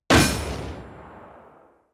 hitmarker2.wav